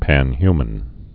(păn-hymən)